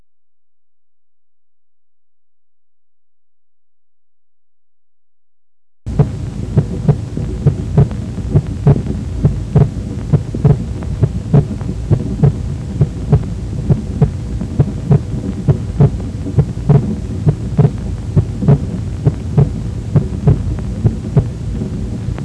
เสียงหัวใจ (Heart sound)
Physiologic splitting  พบในภาวะปกติซึ่งมีแยกห่างของเสียง A2 และ P2
ในช่วงหายใจเข้าและเข้ามาชิดกันในช่วงหายใจออก